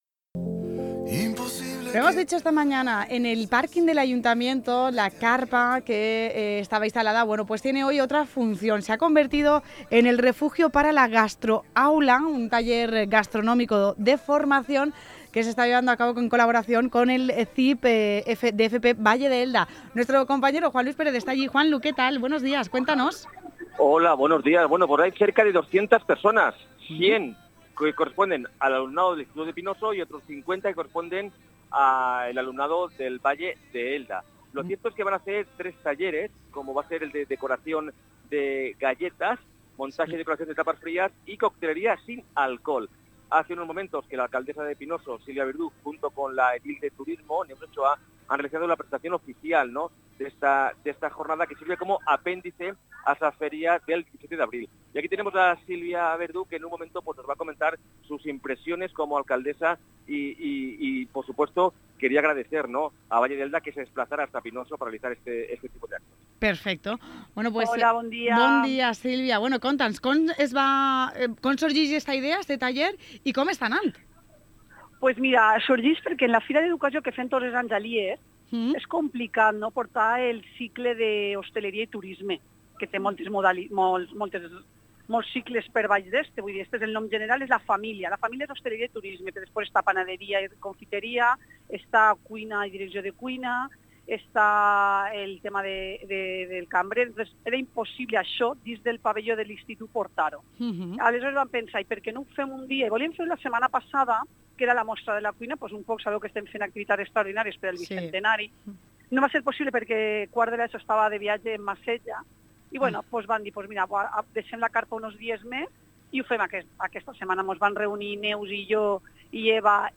Escucha la entrevista con la alcaldesa y edil de Educación Silvia Verdú